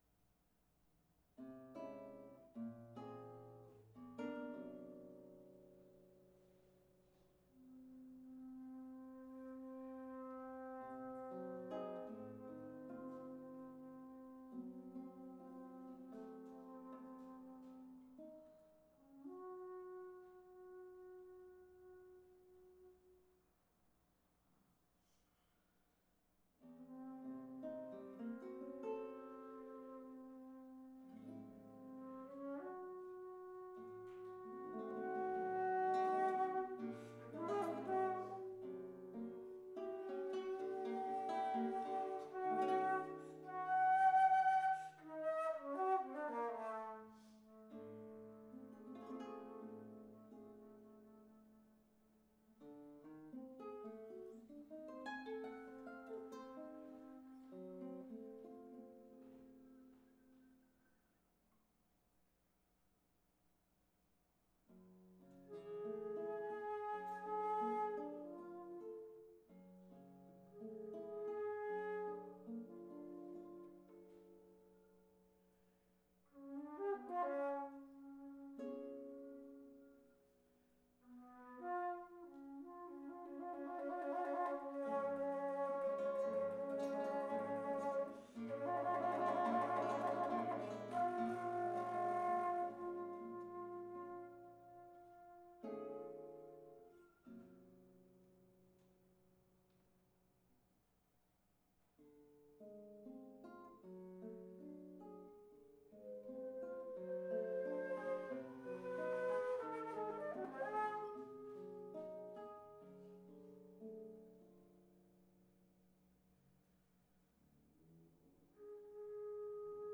guitar
alto flute